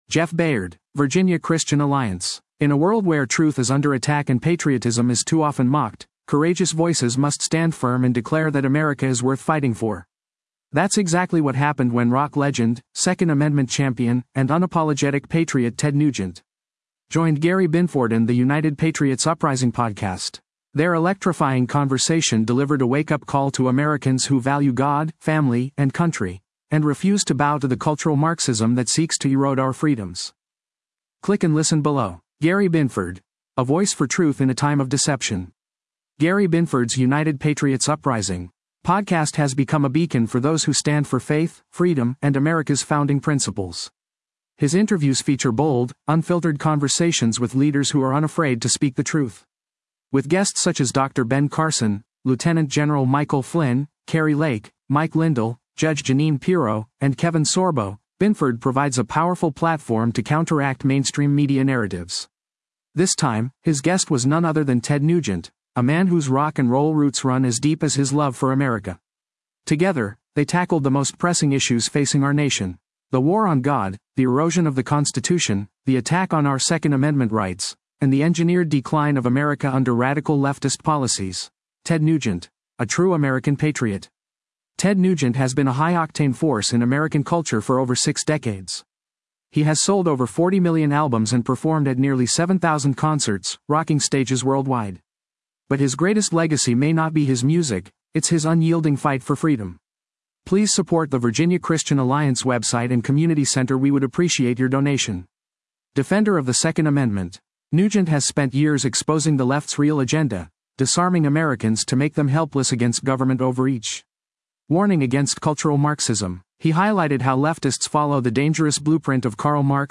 Ted Nugent interview